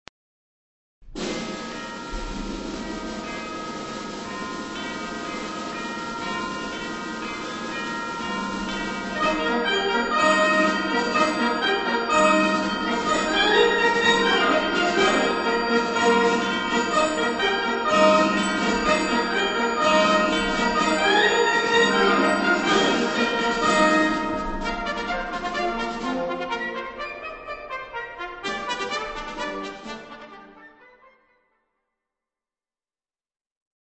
April 2003 Concert